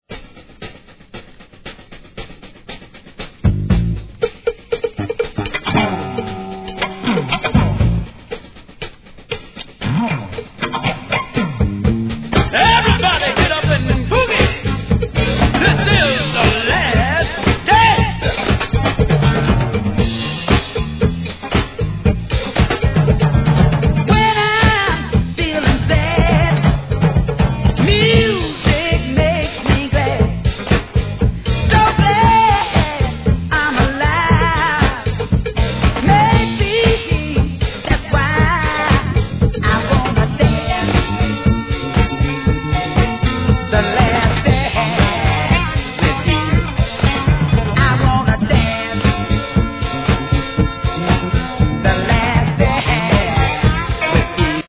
¥ 1,980 税込 関連カテゴリ SOUL/FUNK/etc...